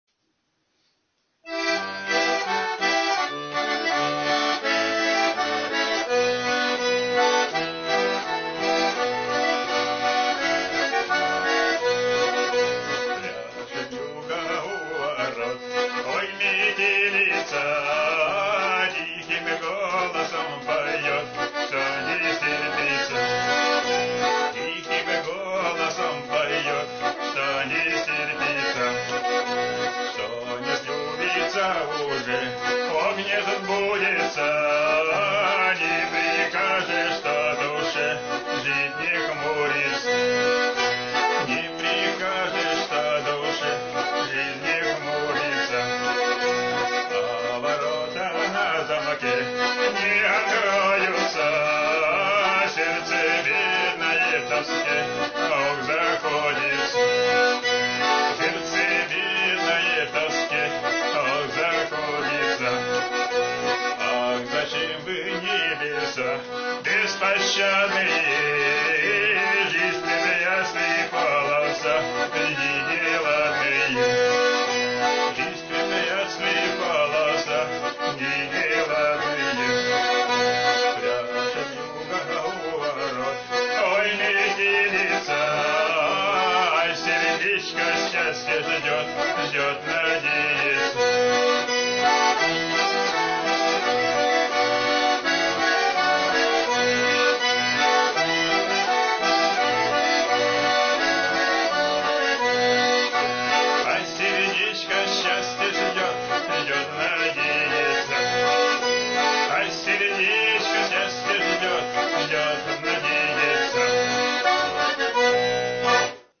Вьюга Песня Гармонь